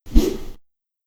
Melee Weapon Air Swing 11.wav